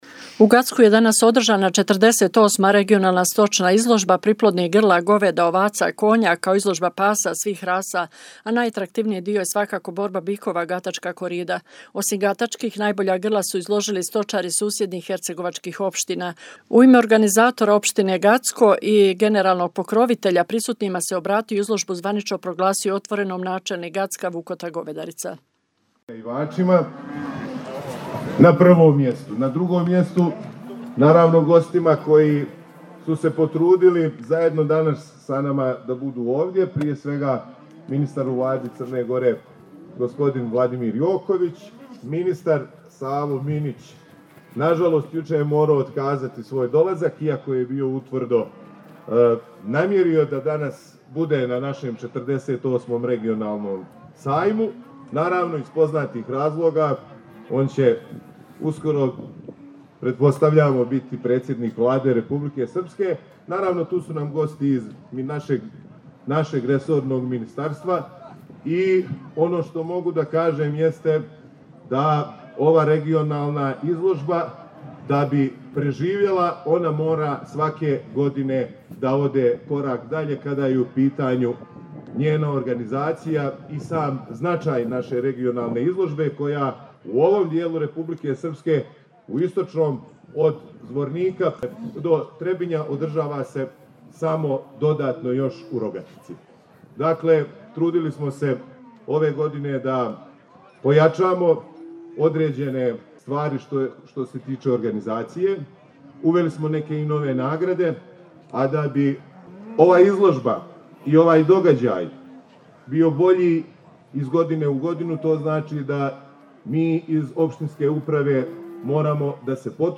Sajam je posjetio i ministar poljoprivrede, šumarstva i vodoprivrede Crne Gore Vladimir Joković i obratio se prisutnima.